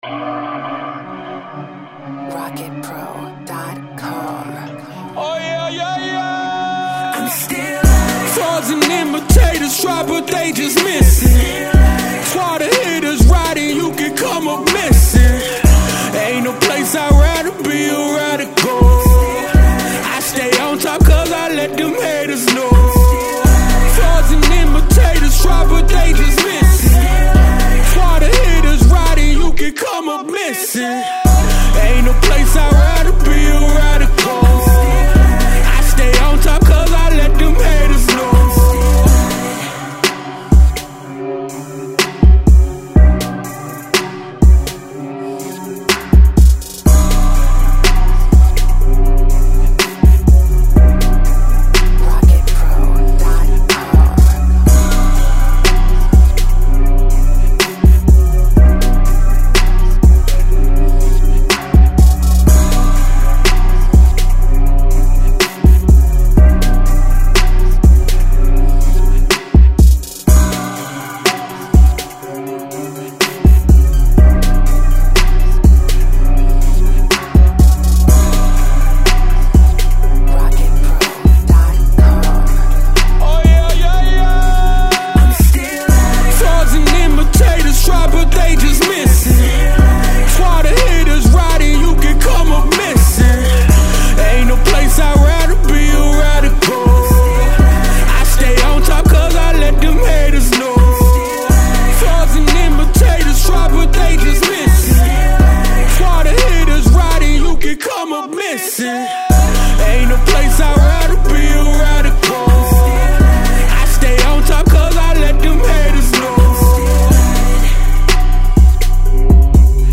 127 BPM.